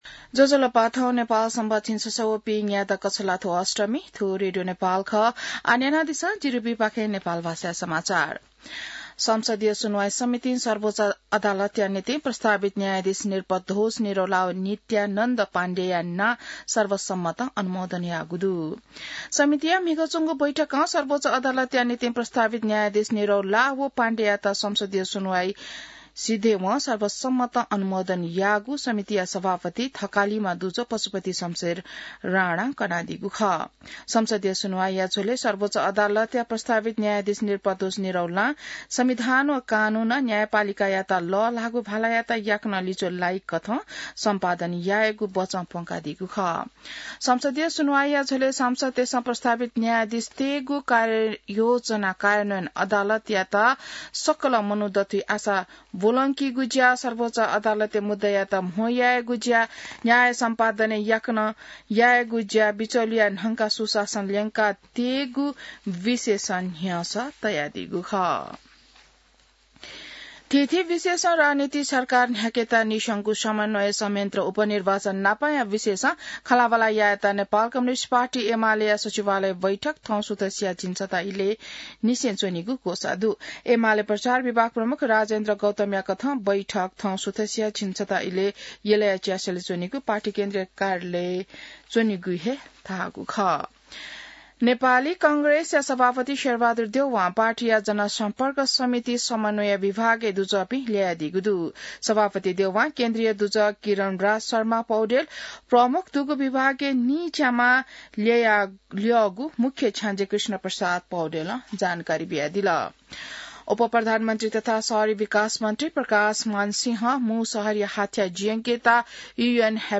नेपाल भाषामा समाचार : २५ कार्तिक , २०८१